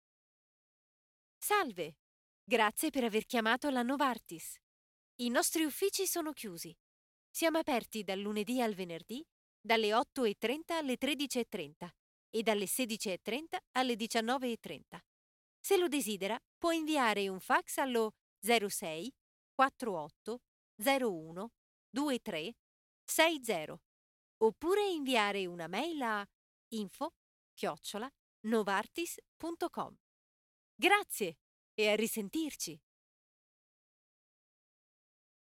Noted for her fresh, friendly & romantic personas.
Records from her home studio or on location in Geneva and other connected cities throughout Switzerland.
Processing includes light noise removal for mouth clicks and breaths and light compression for simple normalization.
Sprechprobe: Sonstiges (Muttersprache):
IVR_0.mp3